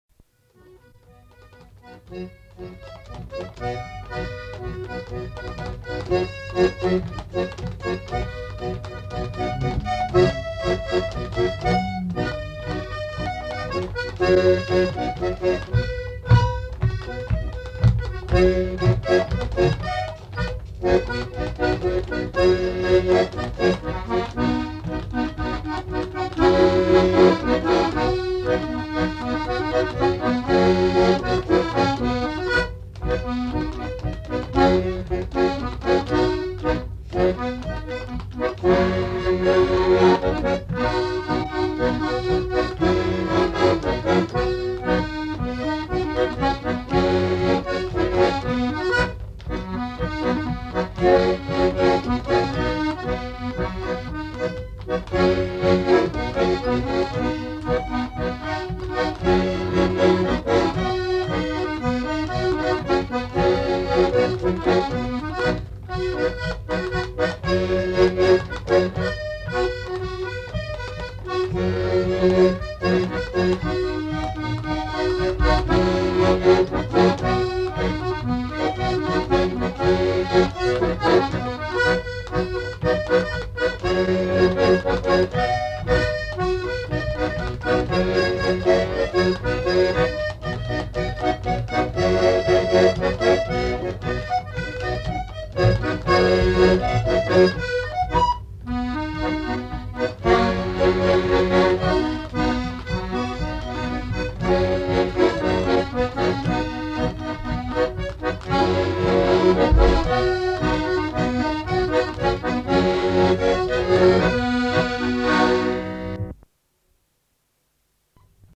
Aire culturelle : Savès
Lieu : Polastron
Genre : morceau instrumental
Instrument de musique : accordéon diatonique
Danse : rondeau